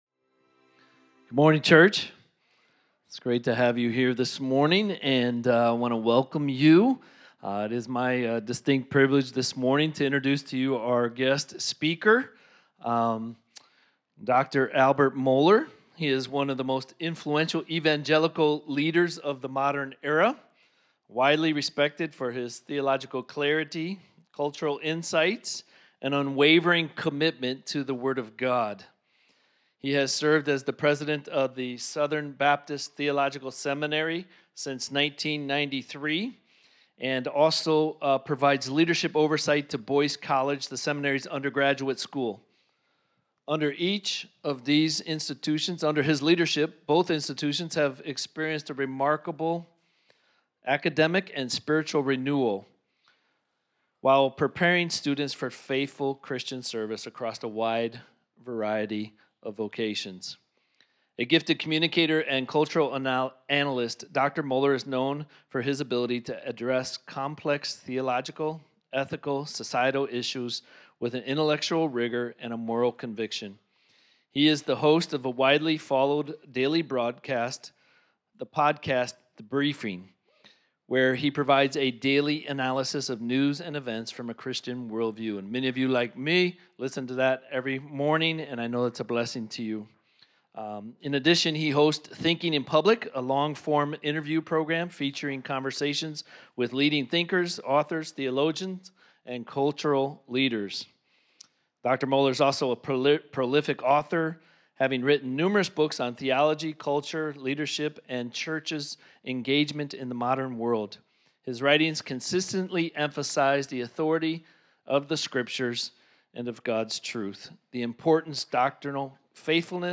Home Sermons Guest Speaker – Dr. Albert Mohler Guest Speaker – Dr. Albert Mohler December 28, 2025 Books: Daniel Speakers: Dr. Albert Mohler Download Save MP3 Scripture Reference Daniel 1 Related